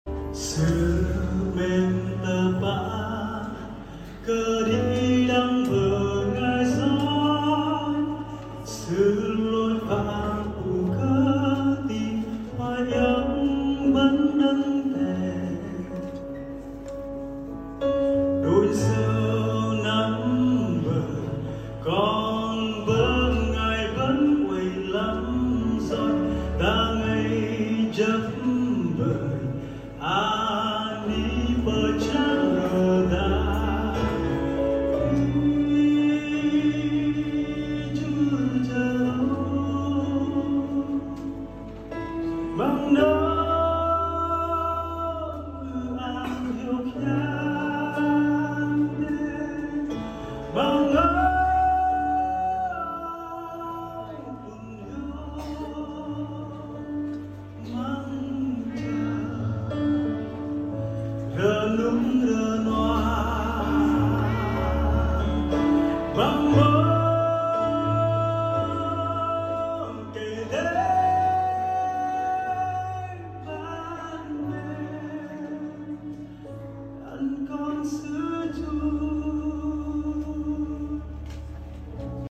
Giọng ca tôn Vinh Chúa bằng tiếng Bana đầy nội lực của anh chàng đến từ Tây Nguyên